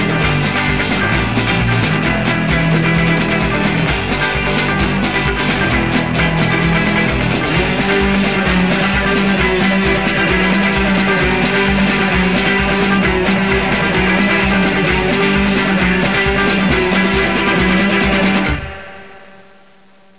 the exit (220K)) is through the instrumental.